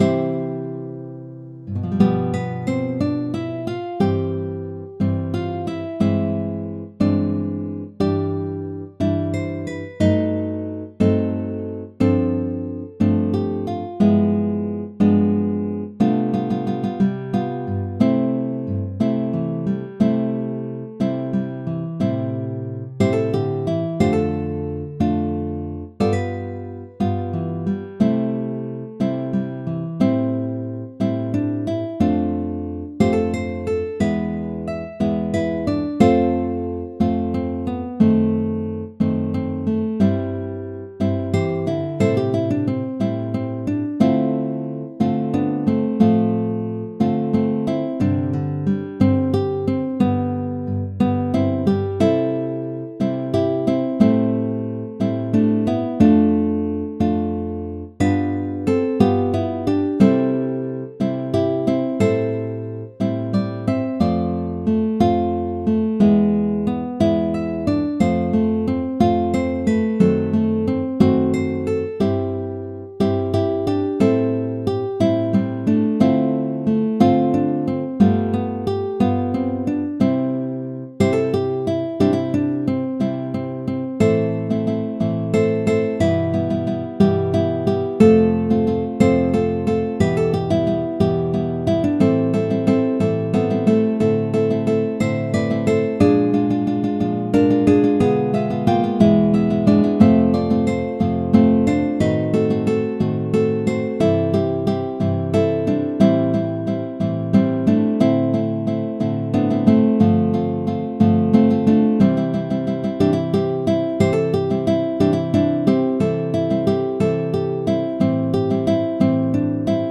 「MP3]はMIDIデータからサウンドフォントを利用して変換した音楽再生用データです。